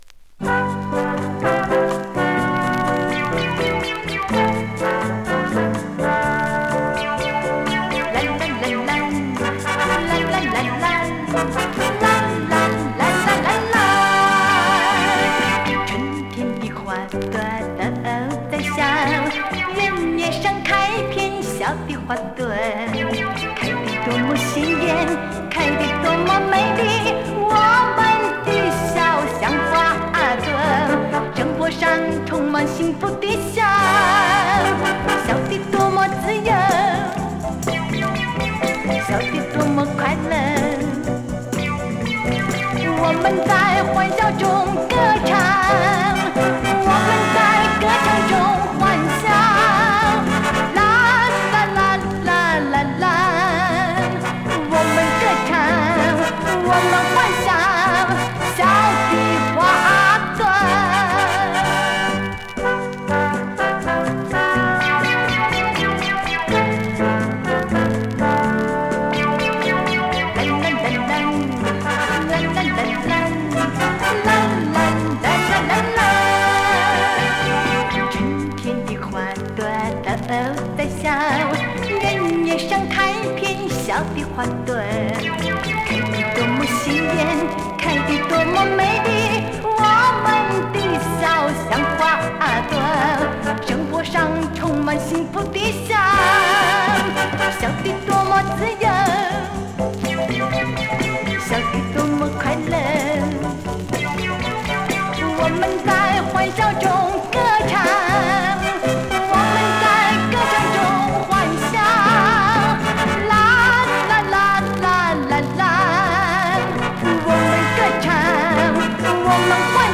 メルヘンなアレンジのポップスが多数収録で、どの曲もシンセ音が肝！独特の空気感を作り出してます！